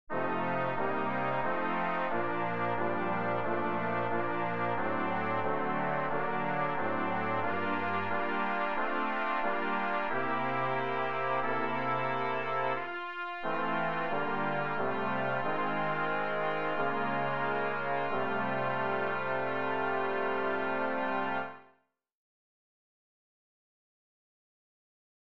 Key written in: F Major
How many parts: 4
Type: Barbershop